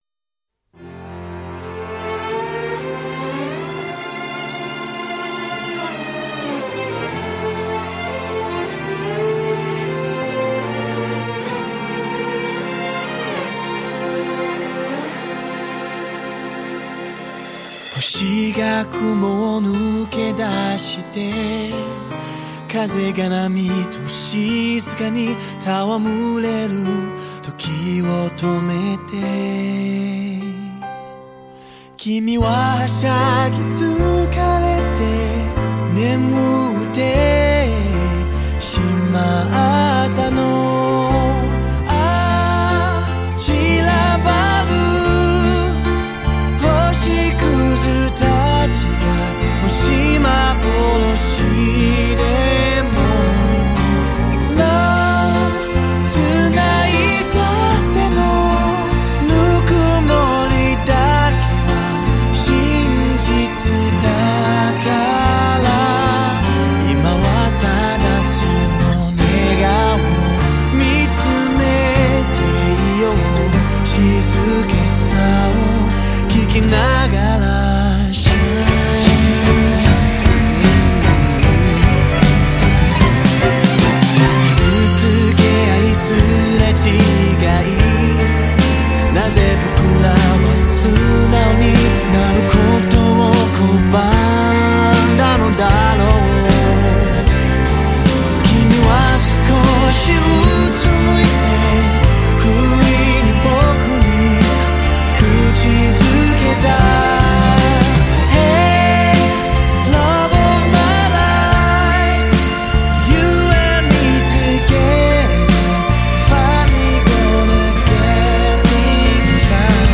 清新民谣风+电子音乐曲风=入耳流行乐
专心制作HDCD全部录制 更显音色澎湃
动听音效自然逼真 为你完美演绎动人的音乐境界